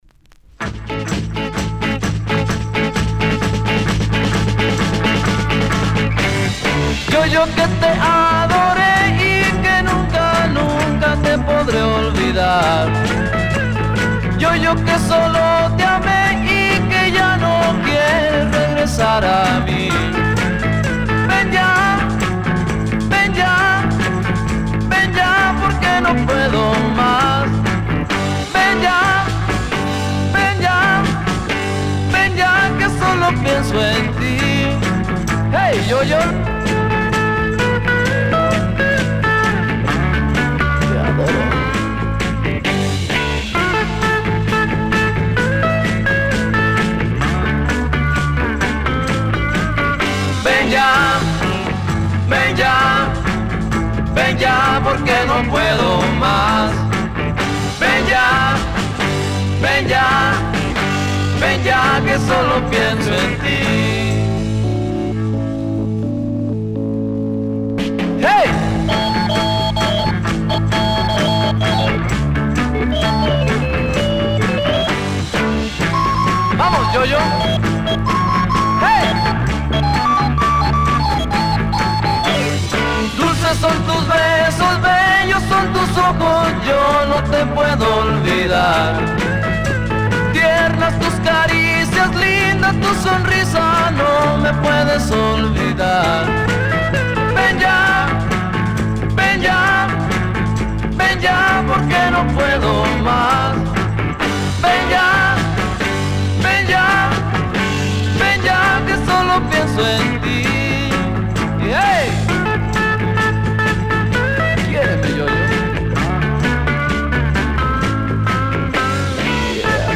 Day 23: Los Covers
Published February 23, 2010 Garage/Rock Comments